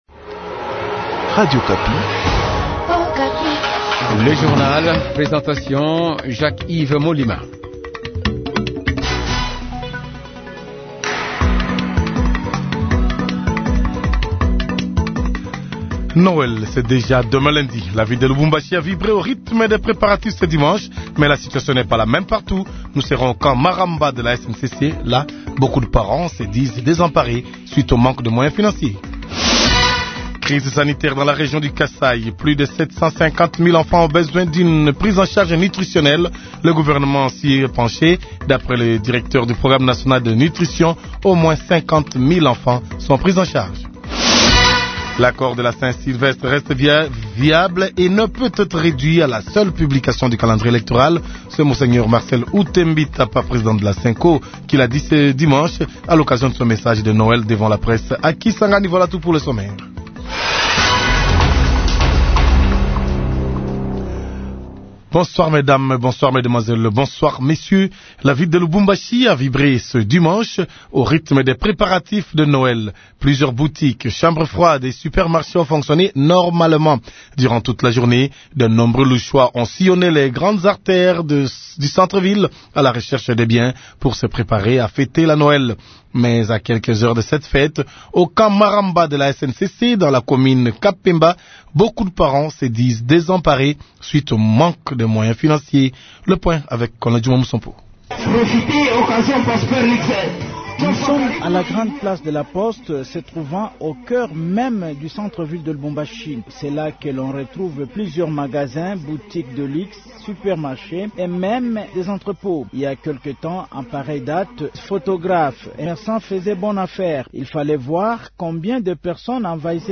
Journal Français Soir